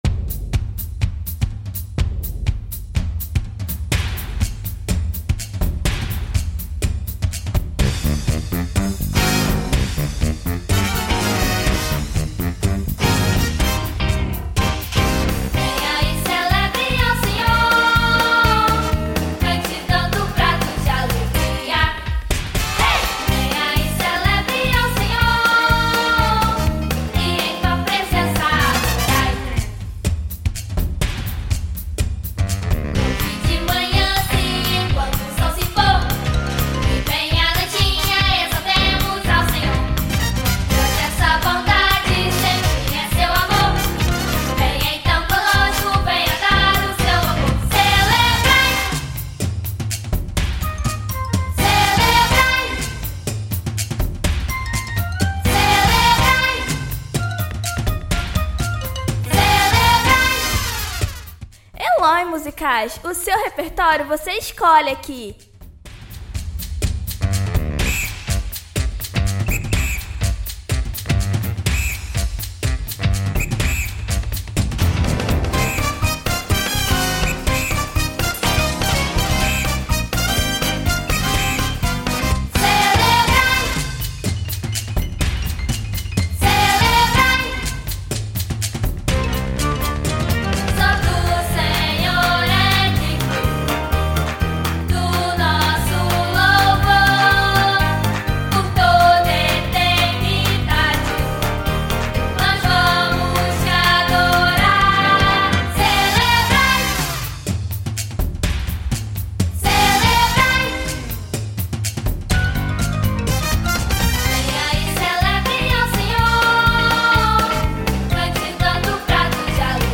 Instrumentos de “batucada” estão ao seu redor.